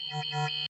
Genel olarak telefonun güçlü özelliklerini yansıtan polifonik ve güçlü tonların bulunduğu bildirim seslerinin firmanın yıllardır süregelen seslerin modern yorumlarını içermektedir.